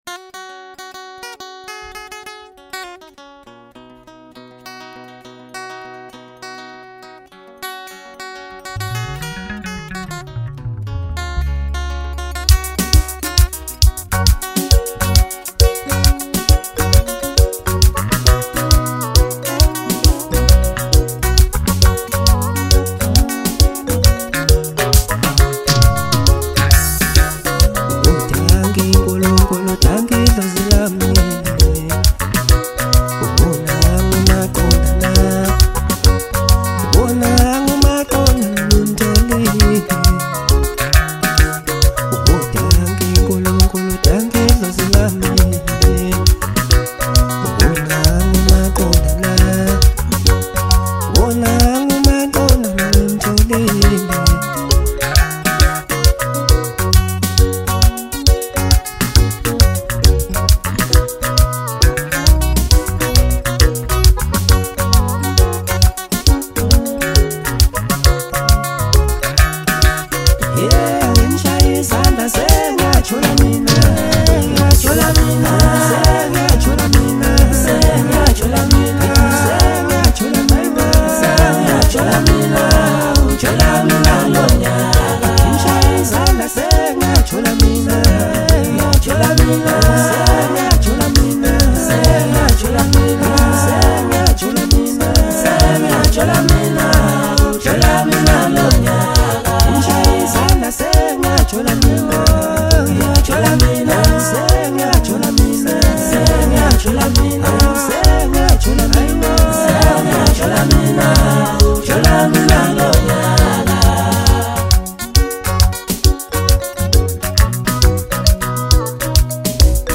South African singer